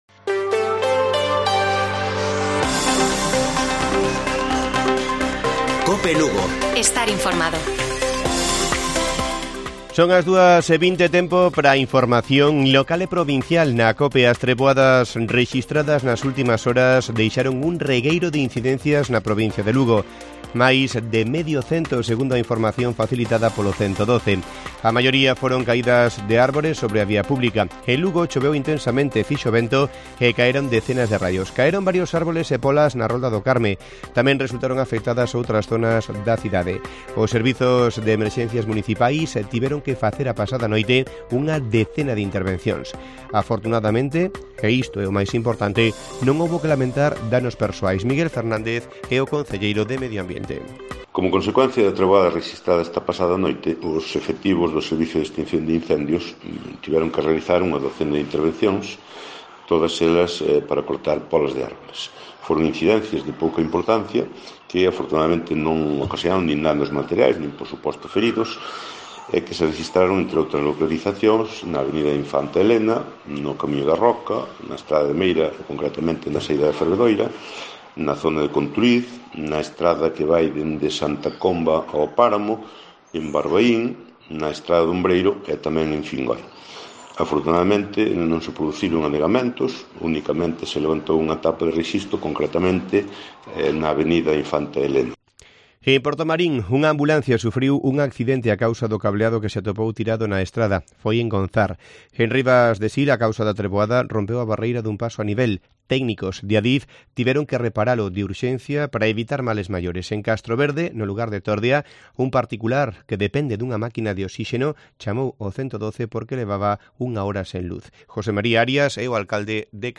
Informativo Mediodía de Cope Lugo. 16 de junio. 14:20 horas